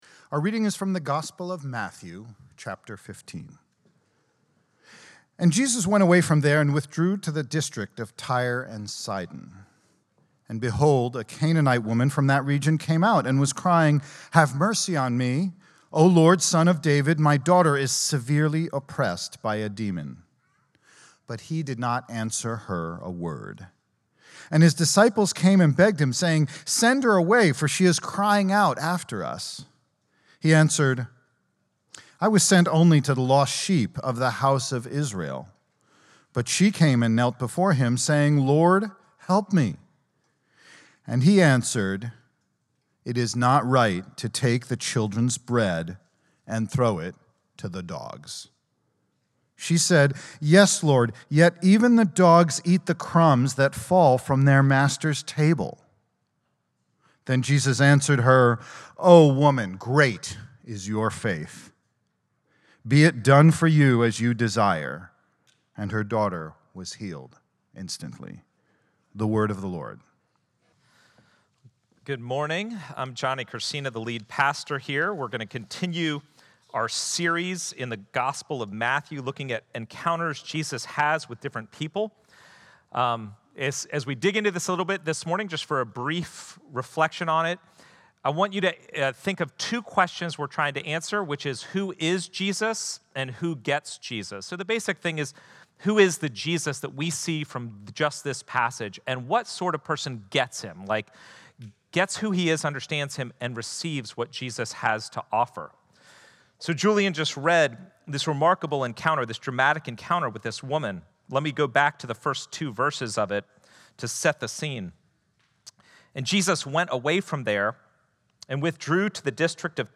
CCV-Sunday-Sermon-3.10.24.mp3